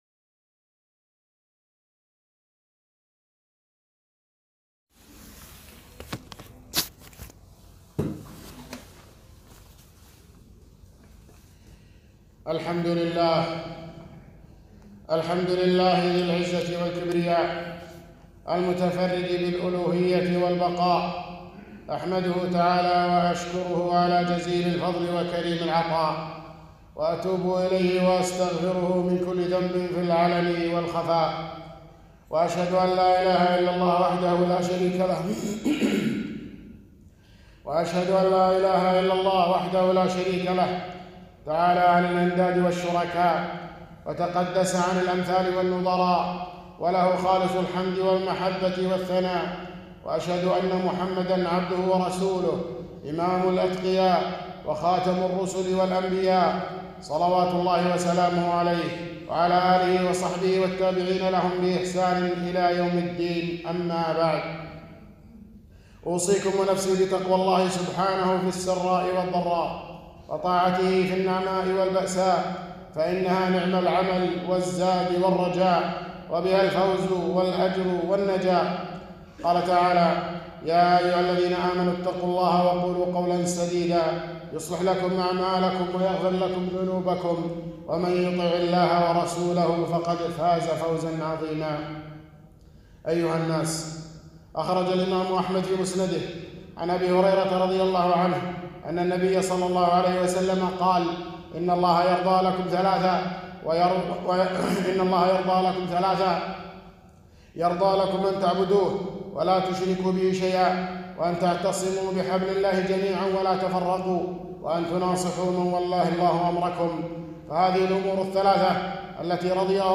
خطبة - إن الله يرضى لكم ثلاثاً